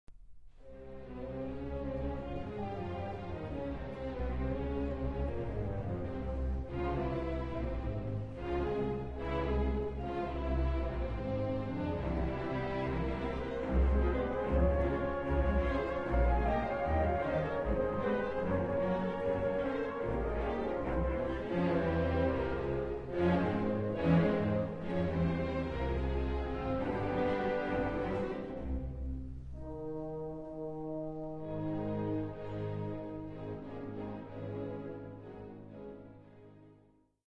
Symphony No. 3 in F major Op. 90